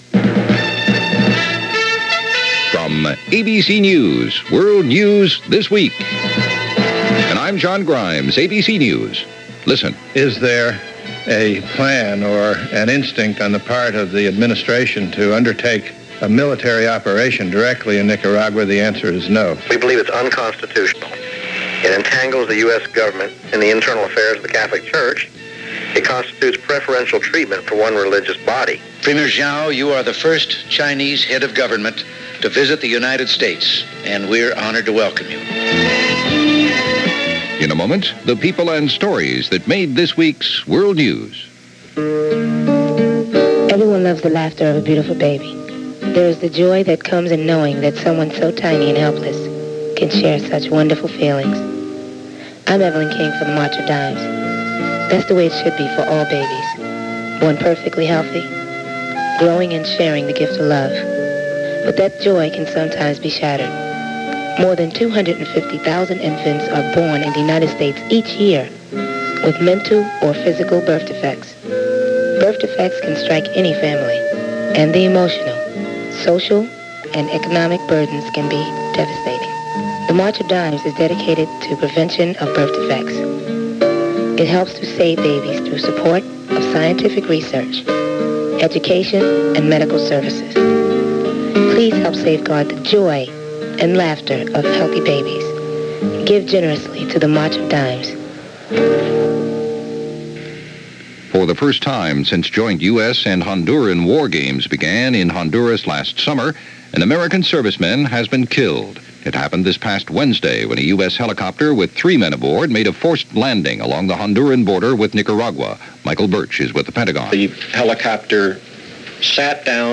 January 15, 1984 - Heating Up In Central America - Grinding On In Lebanon - Mr. Zhao Comes To Washington - news for the week ending January 15, 1984